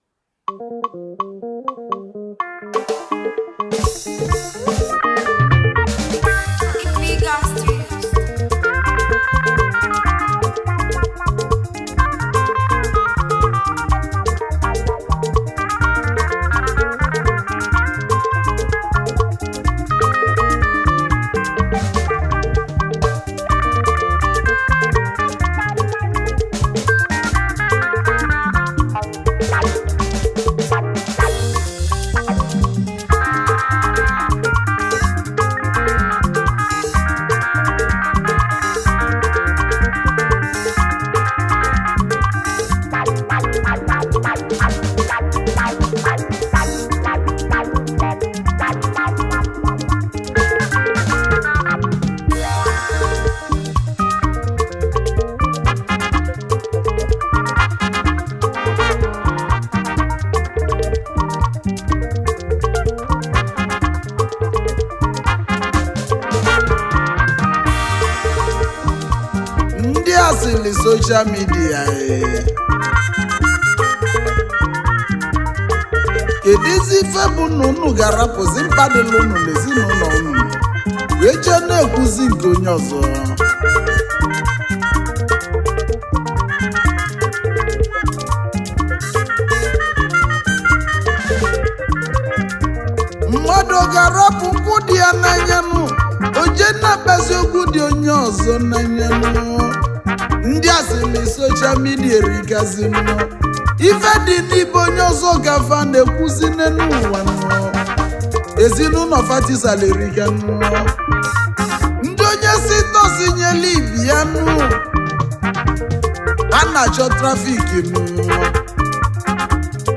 Highlife Ogene